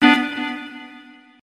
neptunesambient2.wav